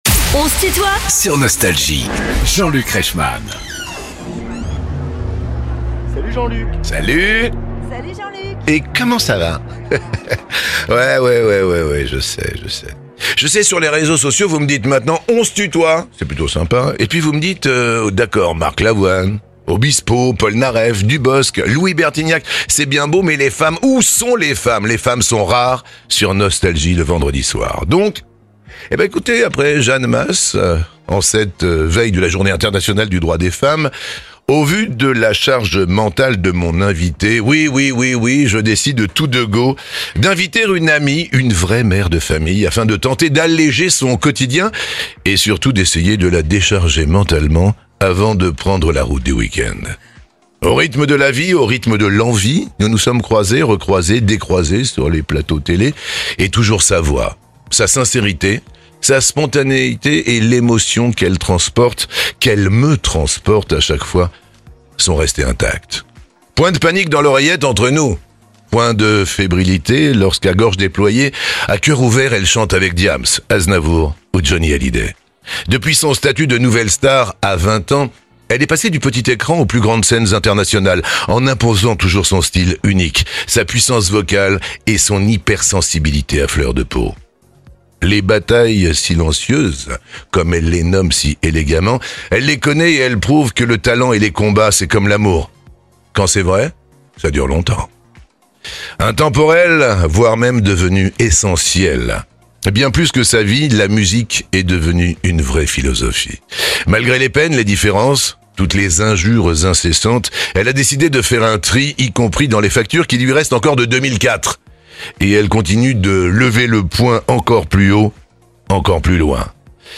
Amel Bent nous raconte son parcours et révèle des anecdotes dans "On se tutoie ?..." avec Jean-Luc Reichmann
Les plus grands artistes sont en interview sur Nostalgie.